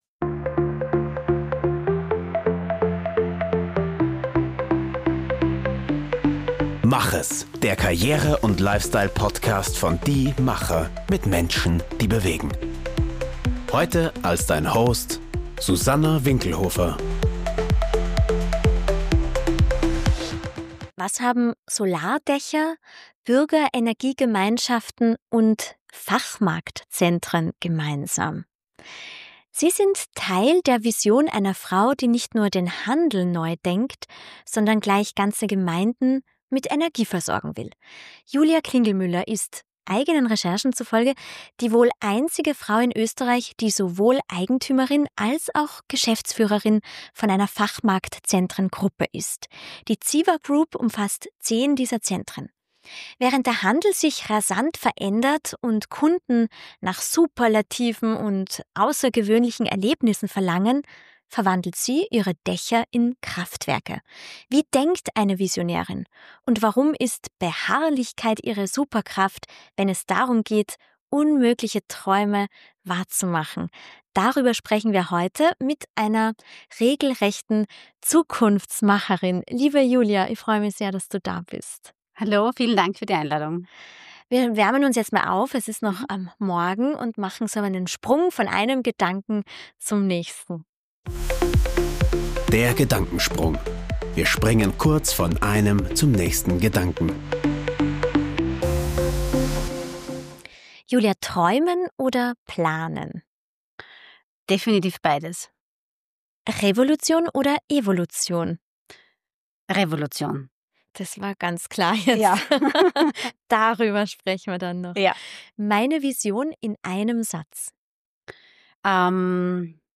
Ein Gespräch über Visionen, Solardächer und die Kunst, nach der fünften Absage weiterzumachen.